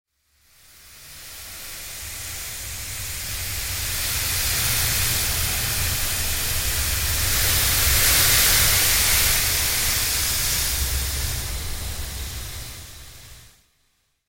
دانلود آهنگ باد 48 از افکت صوتی طبیعت و محیط
جلوه های صوتی
دانلود صدای باد 48 از ساعد نیوز با لینک مستقیم و کیفیت بالا